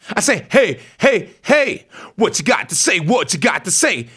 RAPHRASE05.wav